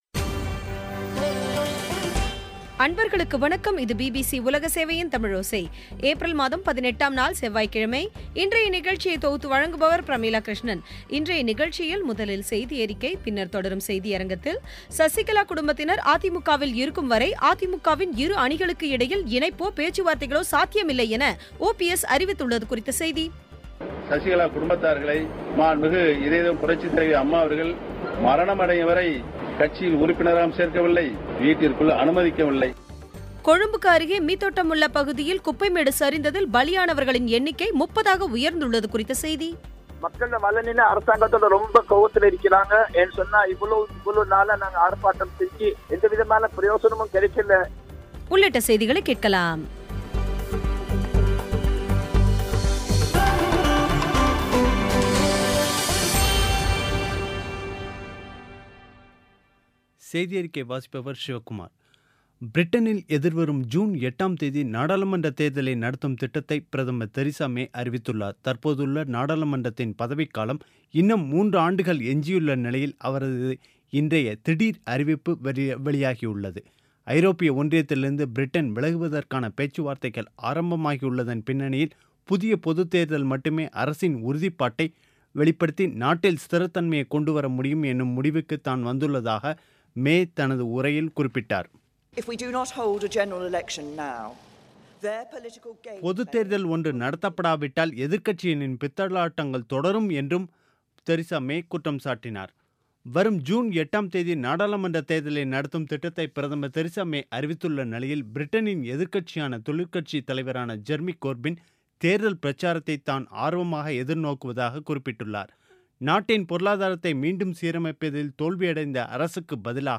இன்றைய நிகழ்ச்சியில் முதலில் செய்தியறிக்கை, பின்னர் தொடரும் செய்தியரங்கில் சசிகலா குடும்பத்தினர் அ.தி.மு.கவில் இருக்கும்வரை இணைப்போ பேச்சுவார்த்தைகளோ சாத்தியமில்லை என ஓபி எஸ் அறிவித்துள்ளது குறித்த செய்தி கொழும்புக்கு அருகே மீத்தொட்டமுல்ல பகுதியில் குப்பை மேடு சரிந்ததில் பலியானவர்களின் எண்ணிக்கை முப்பதாக உயர்ந்துள்ளது குறித்த செய்தி உள்ளிட்டவை கேட்கலாம்